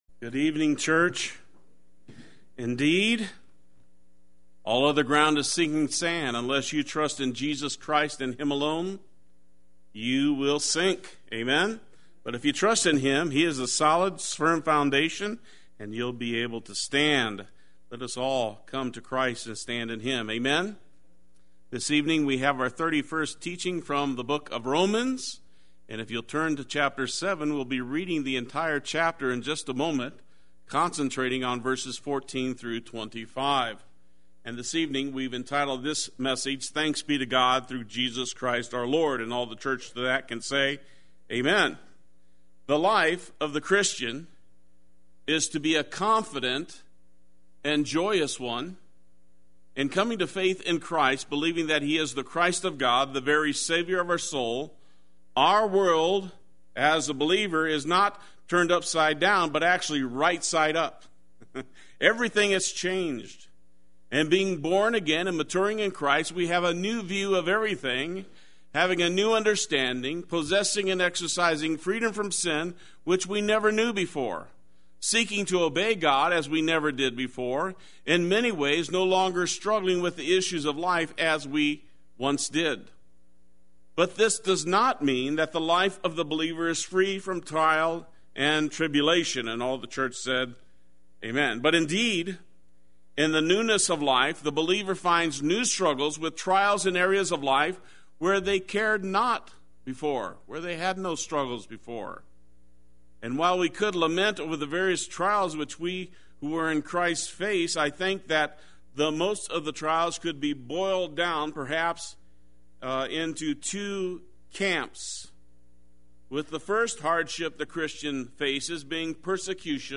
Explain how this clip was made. Thanks Be to God Through Jesus Christ our Lord Wednesday Worship